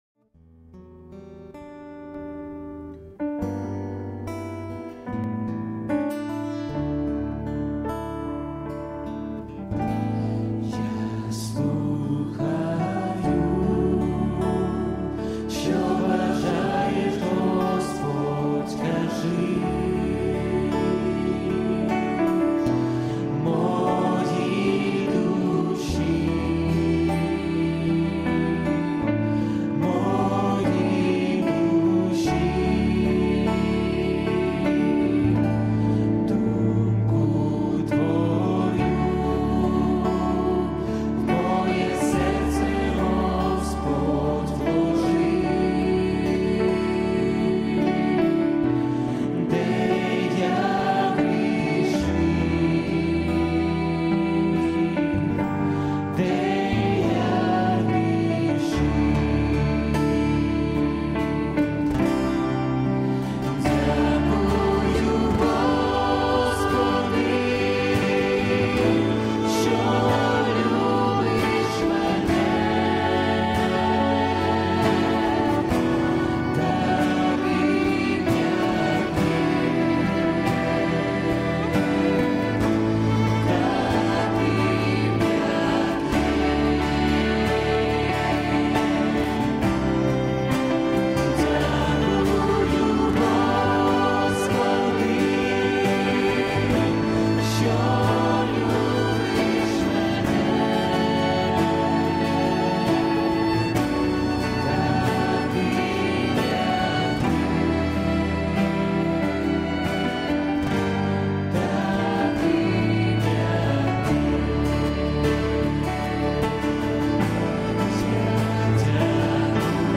Worship Songs